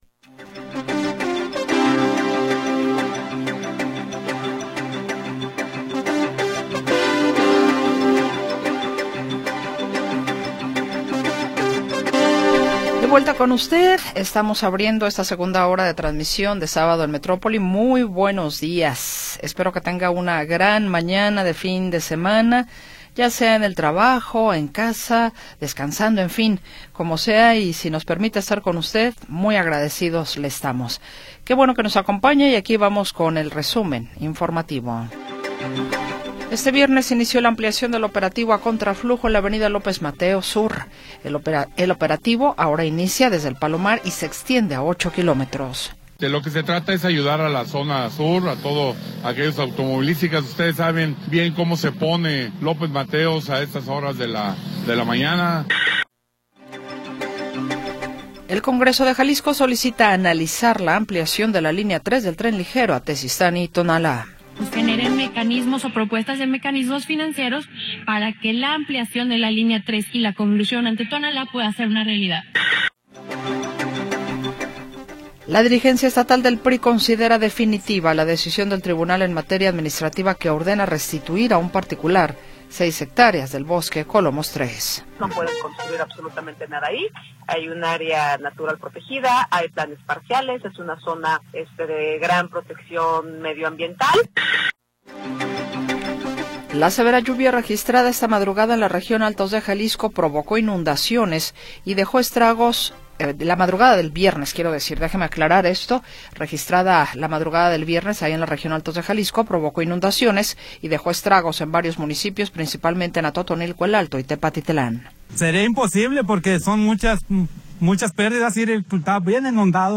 30 de Agosto de 2025 audio Noticias y entrevistas sobre sucesos del momento